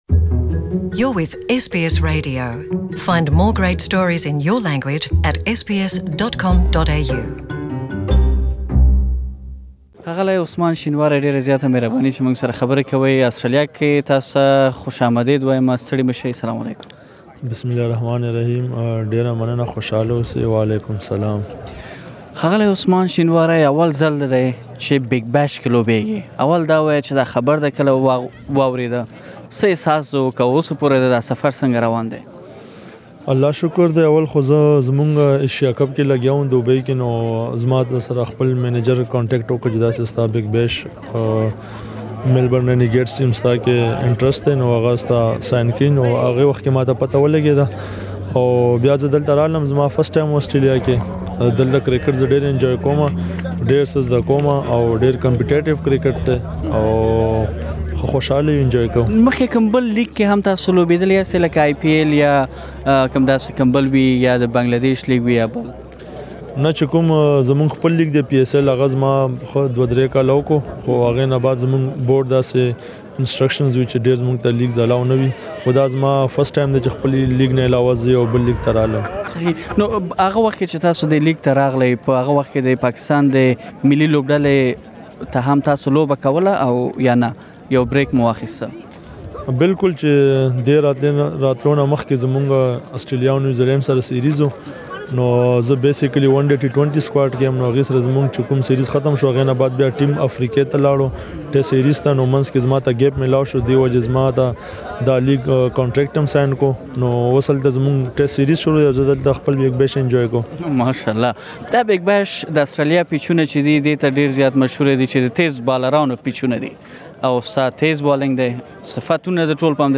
Please listen to the full interview in Pashto